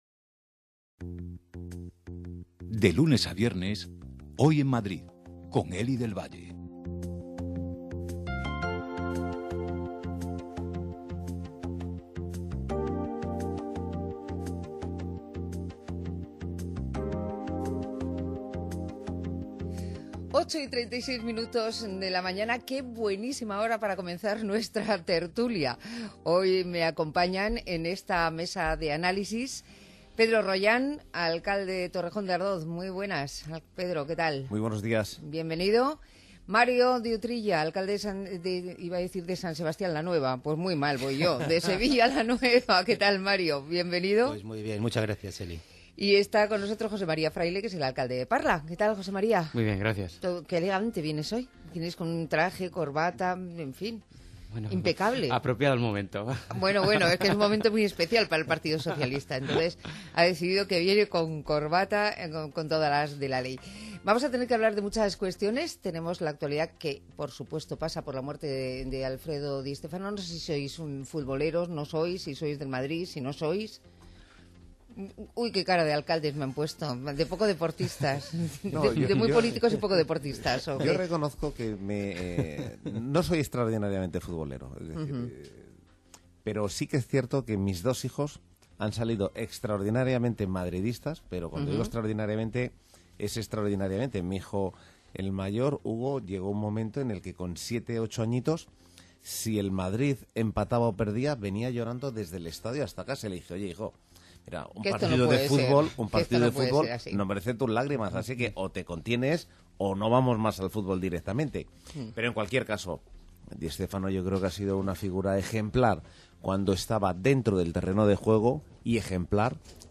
Tertulia de alcaldes con Mario Utrilla (Sevilla la Nueva), Pedro Rollán (Torrejón) y José Mª Fraile (Parla)